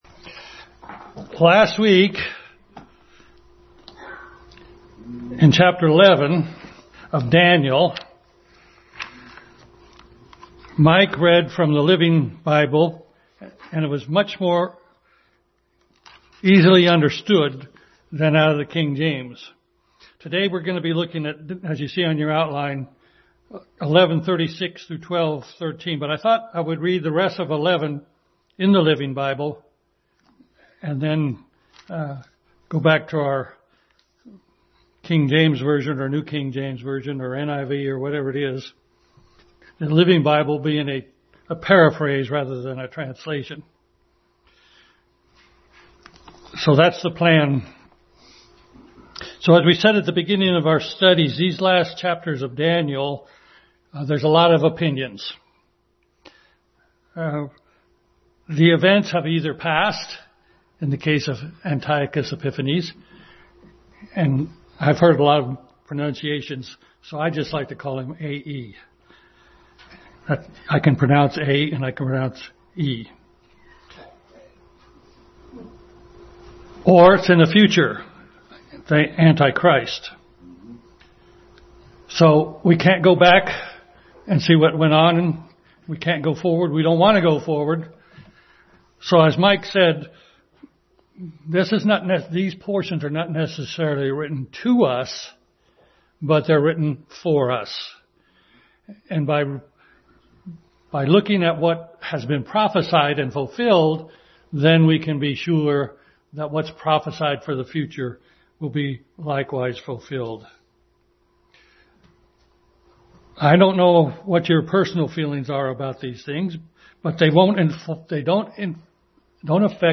Daniel 11:36-12:13 Passage: Daniel 11:36-12:13 Service Type: Sunday School